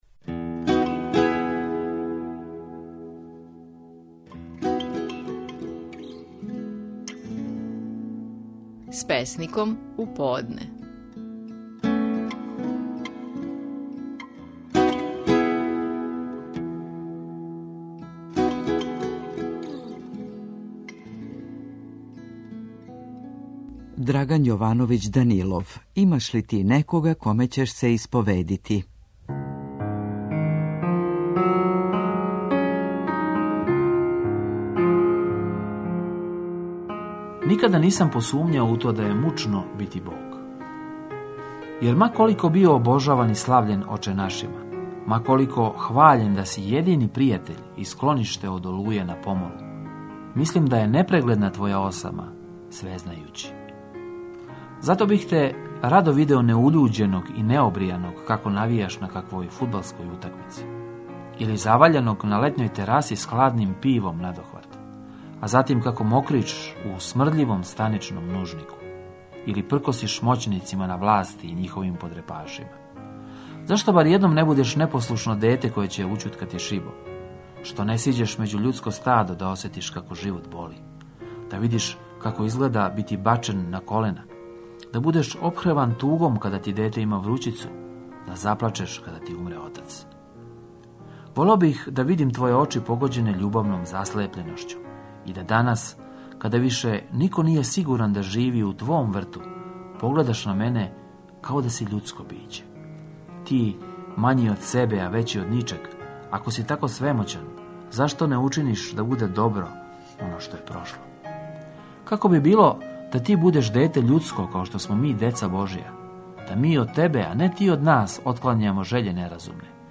Наши најпознатији песници говоре своје стихове
Слушамо песника Драгана Јовановића Данилова који говори своју песму: "Имаш ли и ти некога коме ћеш се исповедити".